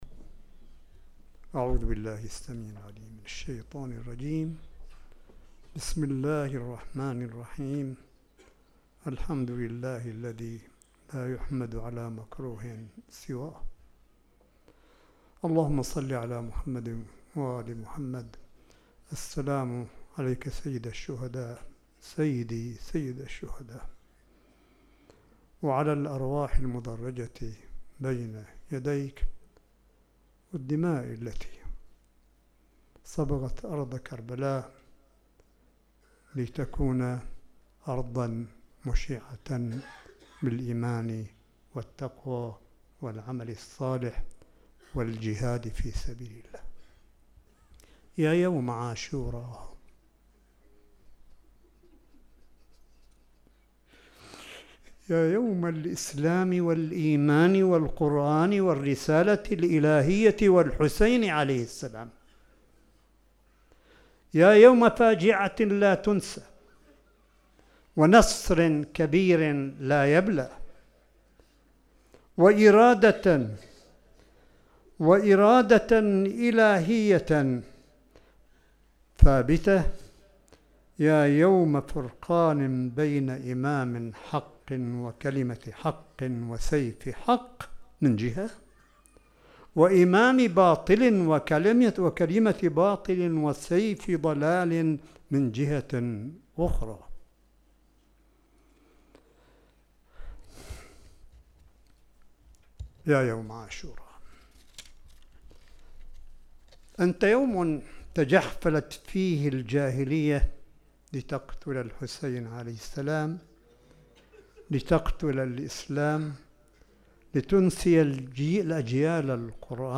ملف صوتي لخطاب عاشوراء المركزي لسماحة آية الله الشيخ عيسى أحمد قاسم، ليلة العاشر من محرم 1441 هـ – الحسينية البحرانية في مدينة قم المقدسة 9 سبتمبر 2019 م